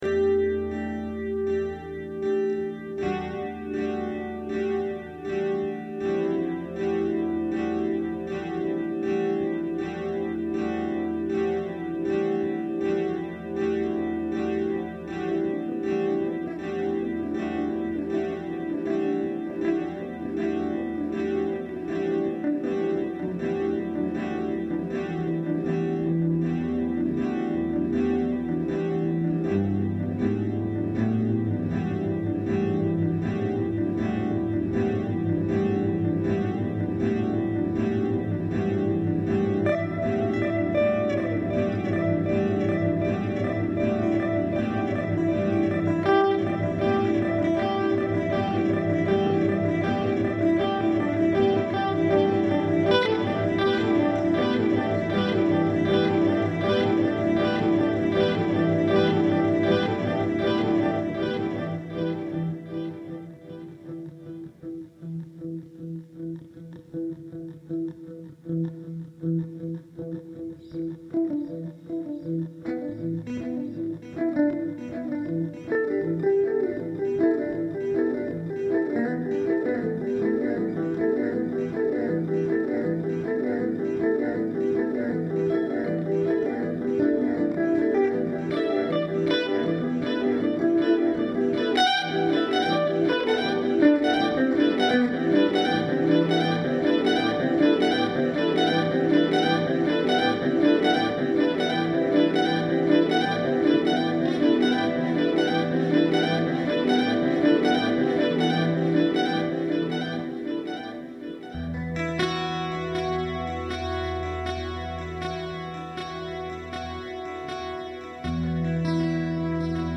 Kein Song oder Solo im klassischen Sinn. Eher eine “Spielerei” mit Delay-Effekt.
Wem es am Anfang zu überladen ist ab 1:00 min wird es ruhiger.